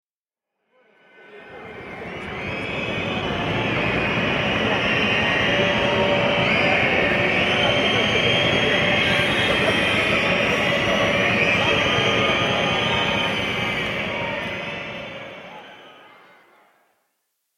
دانلود آهنگ سوت ورزشگاه از افکت صوتی طبیعت و محیط
دانلود صدای سوت ورزشگاه از ساعد نیوز با لینک مستقیم و کیفیت بالا
جلوه های صوتی